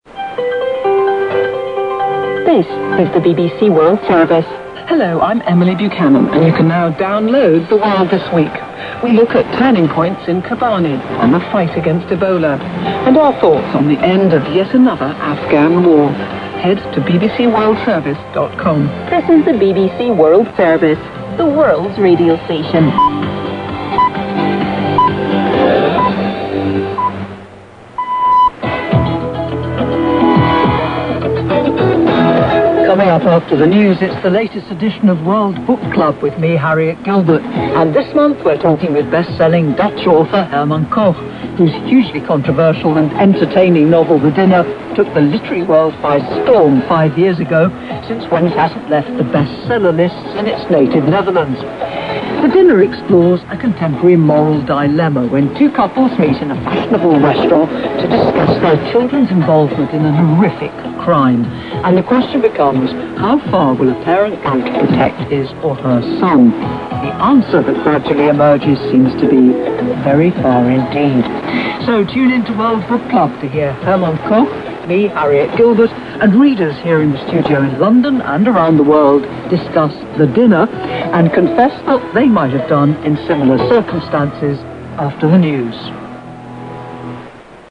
Rádio BBC Frequência 11.810 Khz